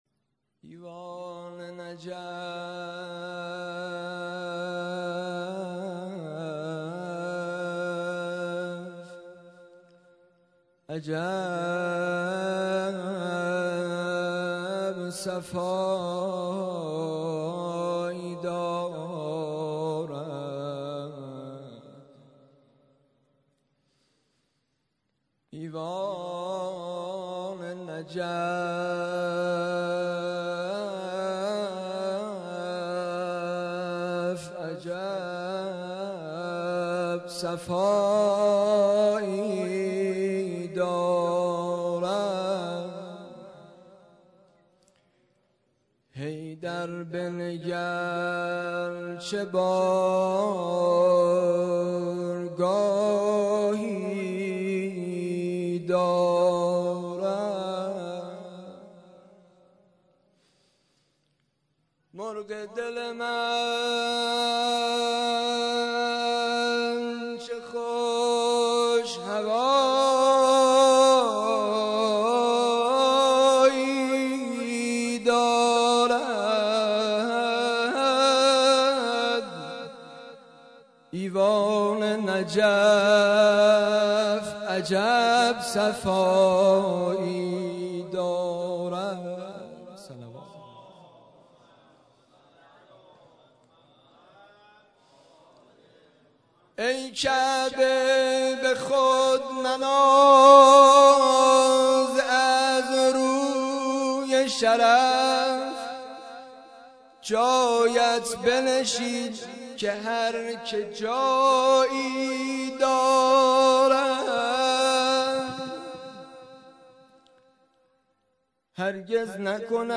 مراسم شب عیدغدیر مهر ۱۳۹۳
مولودی